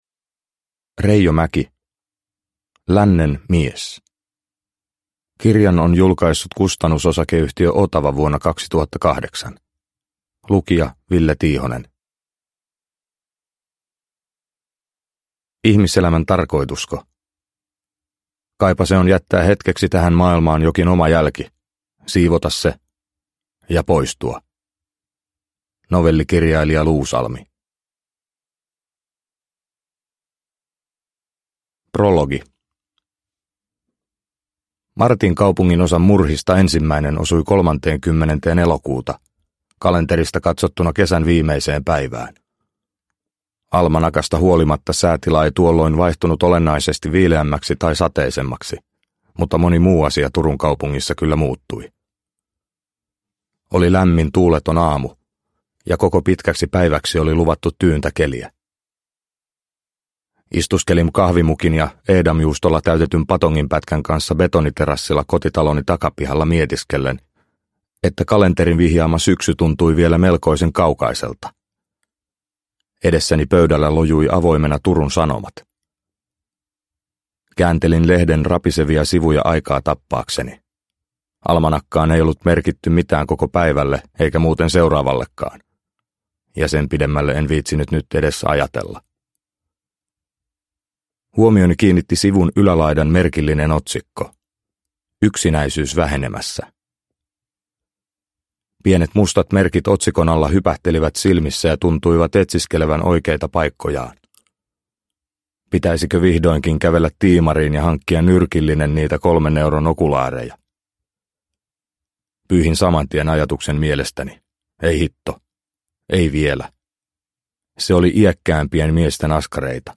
Lännen mies – Ljudbok – Laddas ner